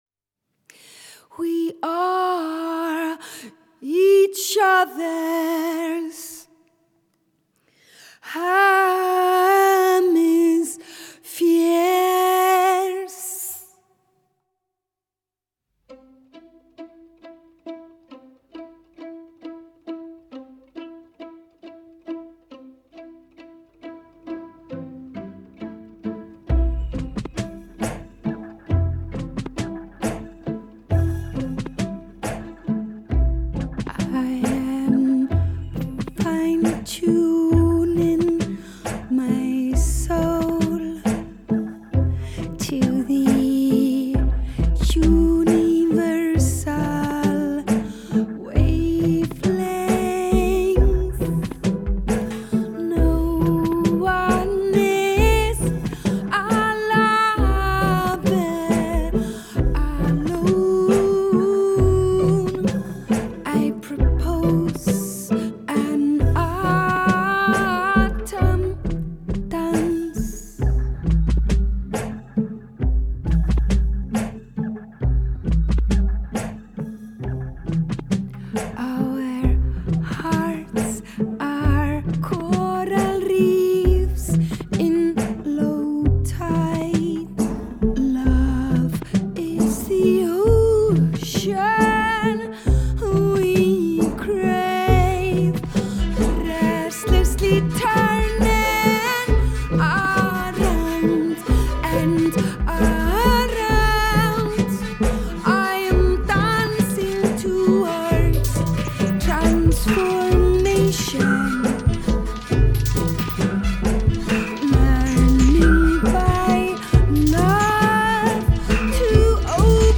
Жанр: Альтернативная музыка.